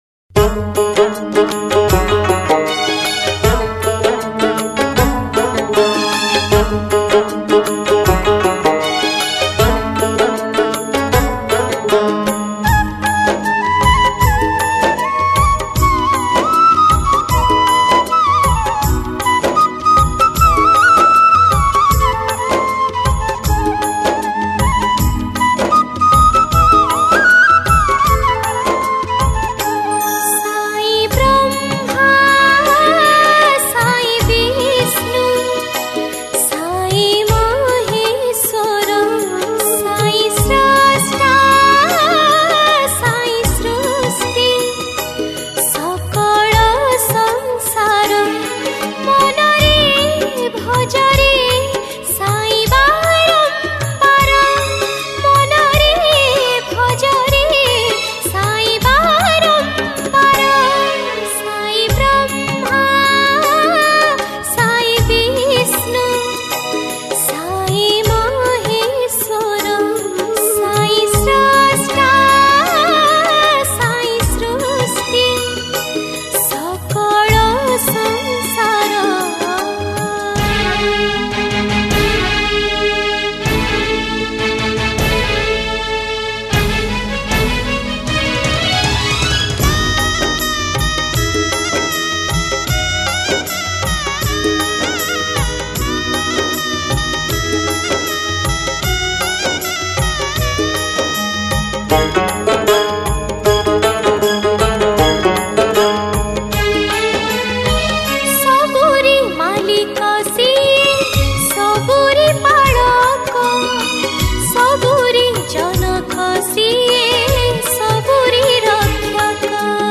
Odia Bhajan
Category: Odia Bhakti Hits Songs